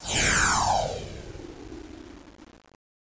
1 channel
00033_Sound_zoom.mp3